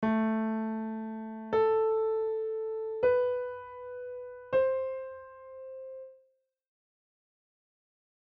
I'm going to play A then up an octave and A
then 2 white notes of my choice